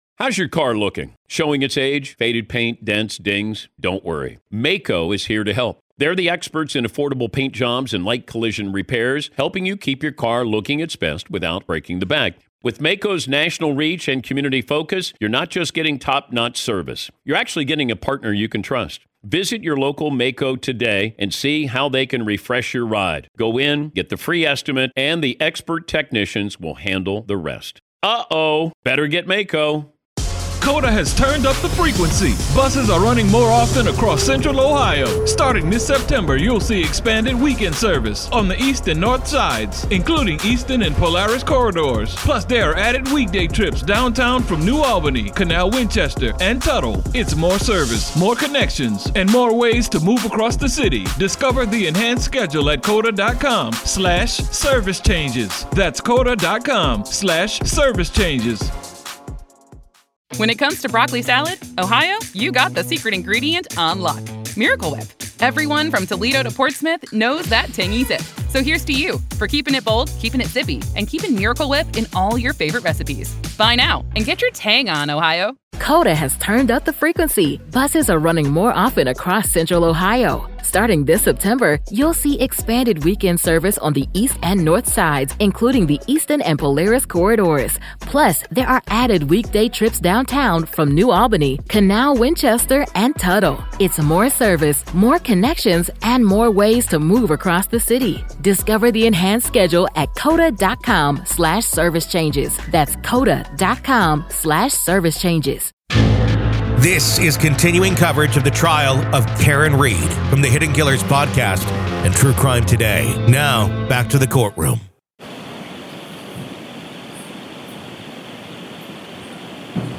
Welcome to a special episode of "The Trial of Karen Read," where today, we find ourselves inside the courtroom of the case against Karen Read. As we set the stage, let's briefly summarize the case that has gripped the public's attention.